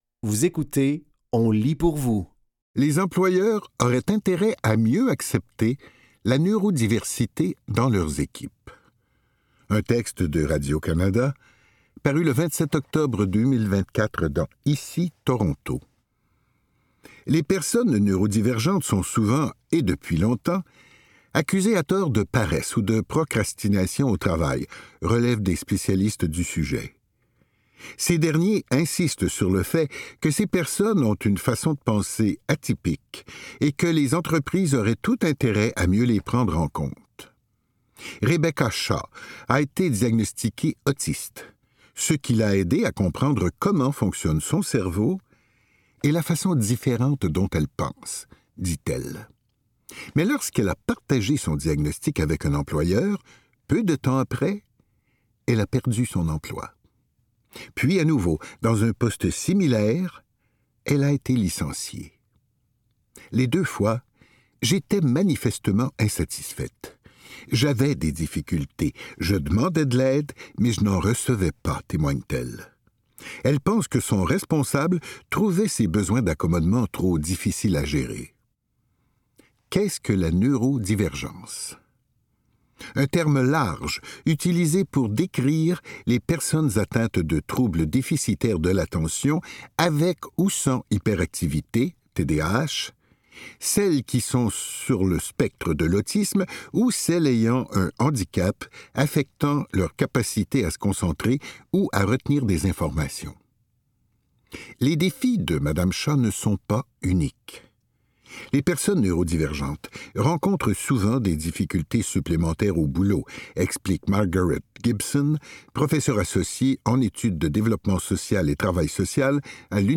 Dans cet épisode de On lit pour vous, nous vous offrons une sélection de textes tirés des médias suivants : Le Devoir, TVA Nouvelles, Châtelaine et Le Journal de Montréal.